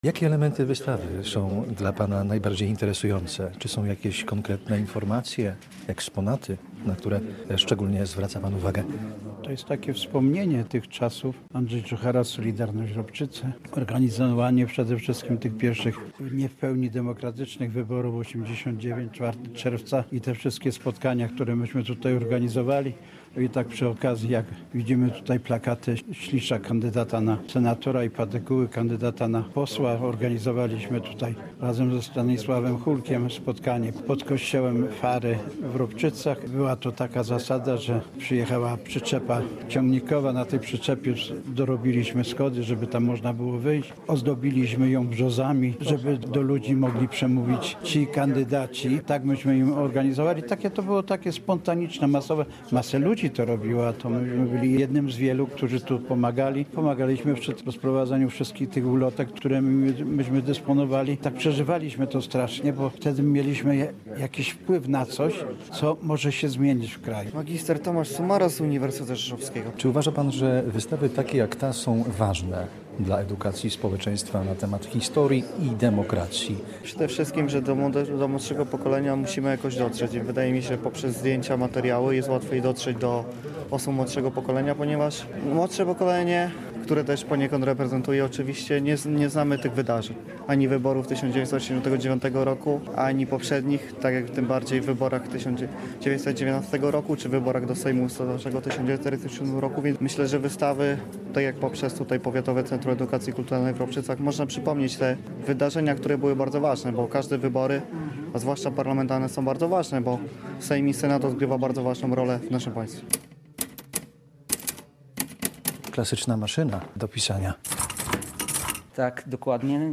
Relacja: